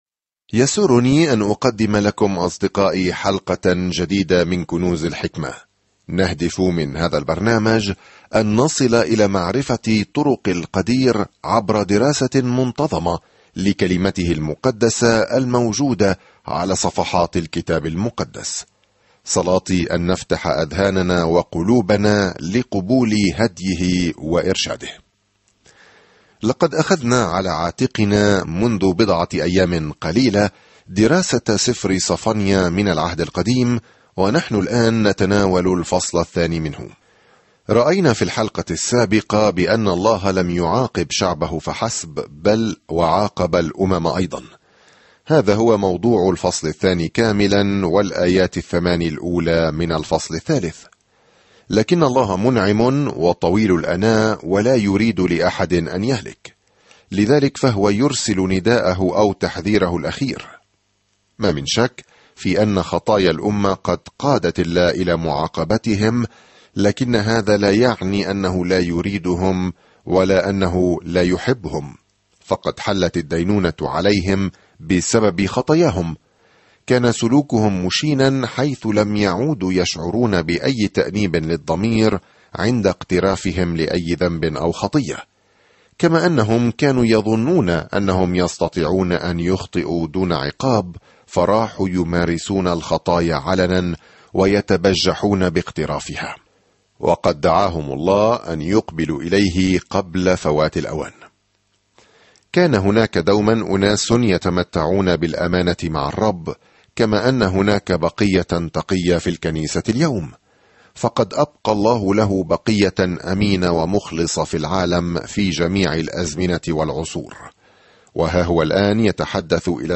There is an audio attachment for this devotional.
الكلمة صَفَنْيَا 8:2-15 صَفَنْيَا 1:3-2 يوم 4 ابدأ هذه الخطة يوم 6 عن هذه الخطة يحذر صفنيا إسرائيل من أن الله سيدينهم، لكنه يخبرهم أيضًا كم يحبهم وكيف سيبتهج بهم يومًا ما بالغناء. سافر يوميًا عبر صفنيا وأنت تستمع إلى الدراسة الصوتية وتقرأ آيات مختارة من كلمة الله.